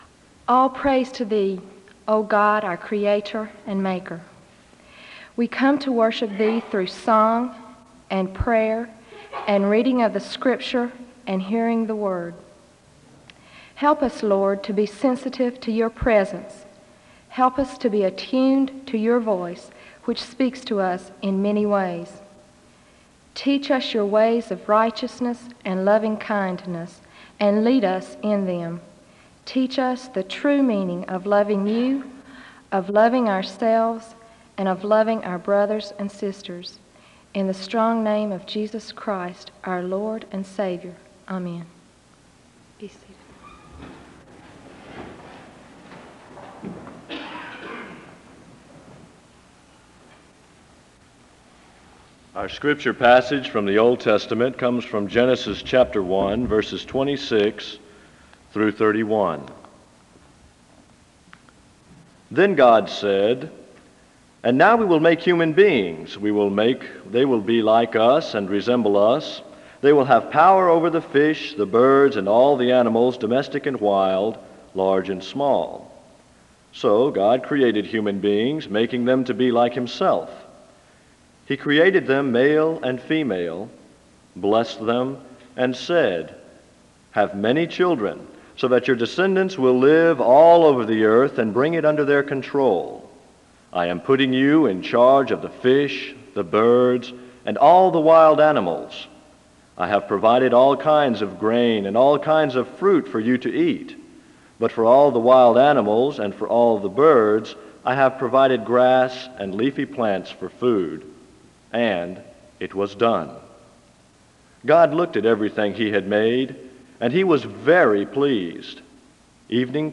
A prayer is given to open chapel (0:00-0:44). Scripture is read from Genesis 1:26-31 (0:44-2:07).
Another reader comes and reads 2 Corinthians 5:15-21 (2:07-3:13). The choir leads in song (3:13-6:16).
A litany is read aloud to close chapel (18:33-21:35).